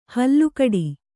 ♪ hallu kaḍi